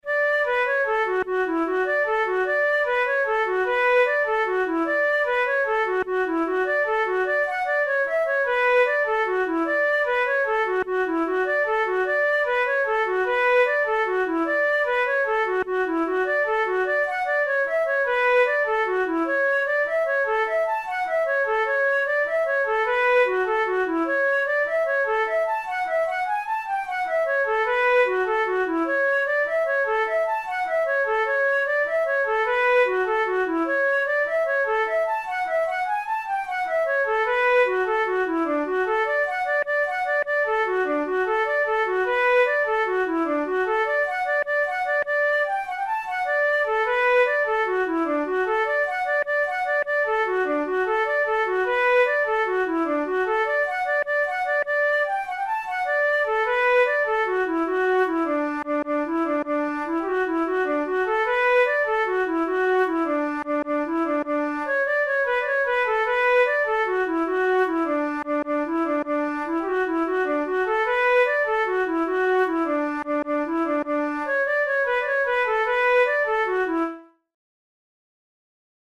Jigs, Traditional/Folk
Traditional Irish jig